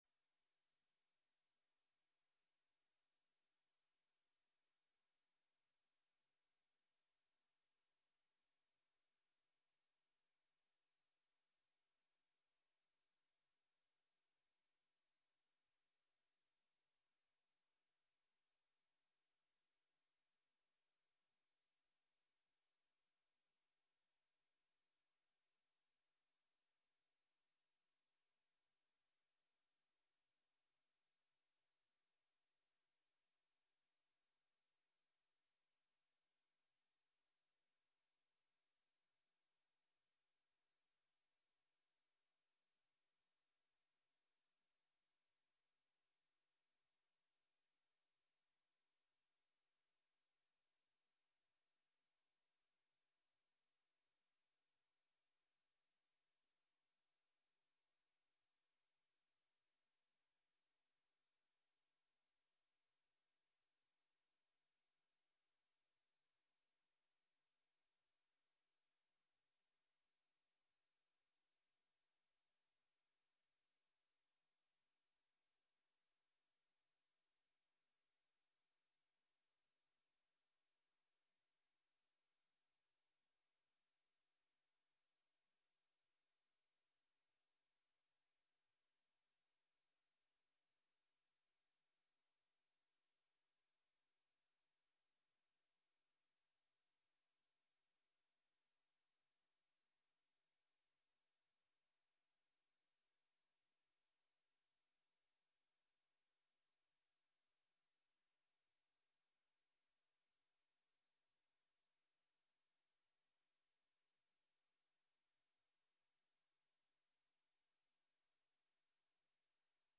Locatie: Stadhuis Raadzaal
Opening en mededelingen door voorzitter Stephan Hugues